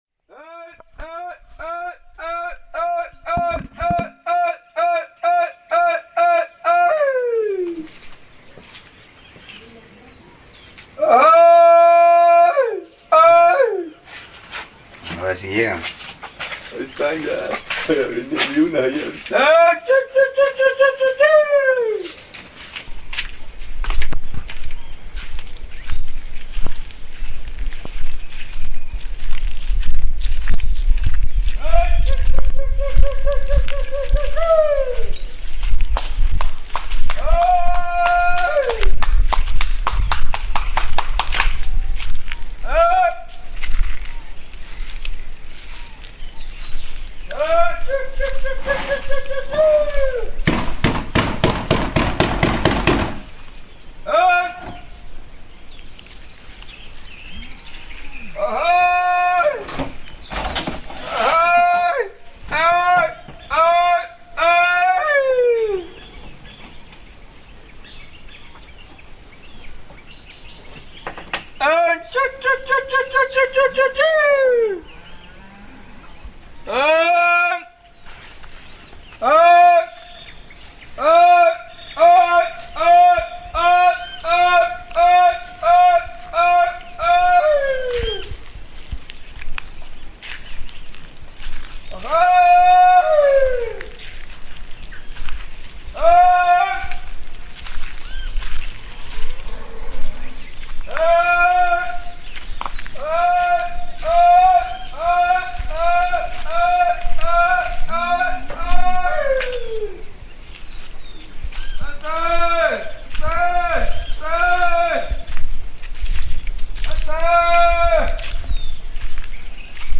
Llamando al ganado
Lugar: Rancho Tencohuey, Azoyú, Guerrero; Mexico.
Equipo: Grabadora Sony ICD-UX80 Stereo